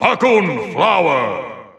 The announcer saying Piranha Plant's name in Japanese and Chinese releases of Super Smash Bros. Ultimate.
Piranha_Plant_Japanese_Announcer_SSBU.wav